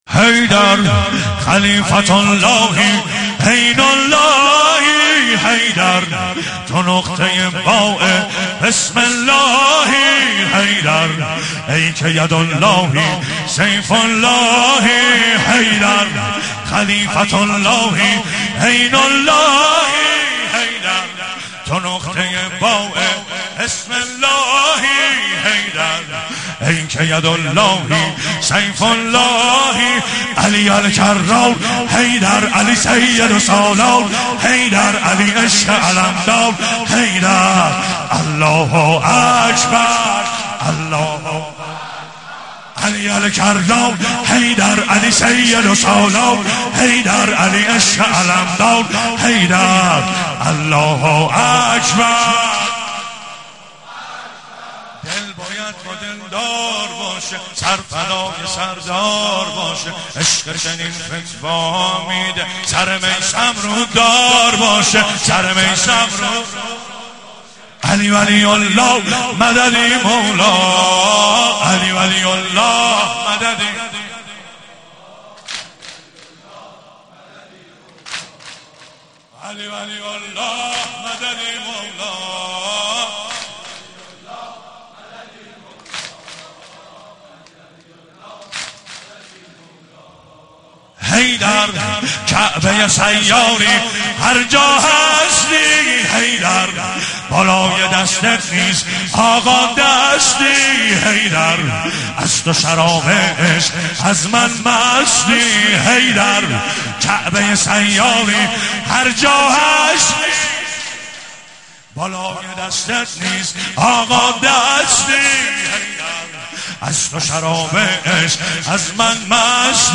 رمضان 89 - سینه زنی 2
رمضان-89---سینه-زنی-2